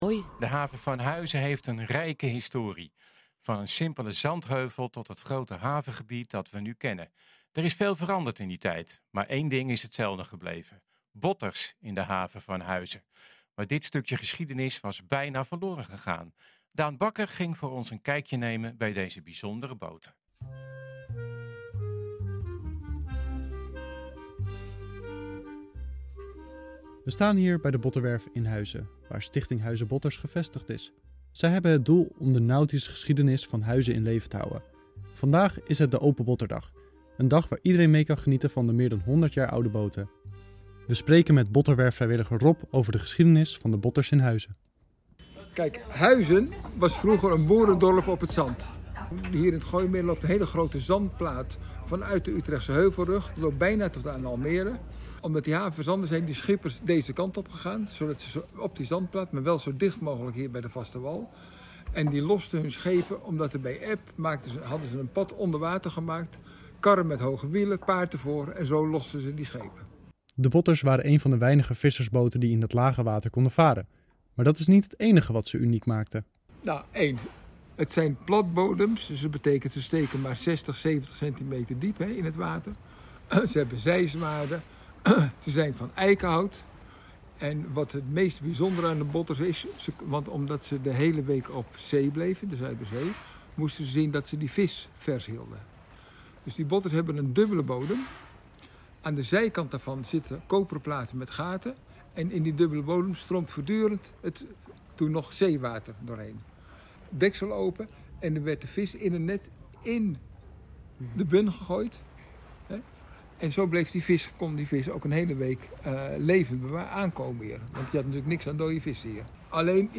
nh-gooi-zaterdag-botters-haven-huizen.mp3